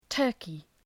Προφορά
{‘tɜ:rkı}